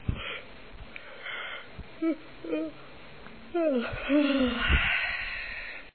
yawn.mp3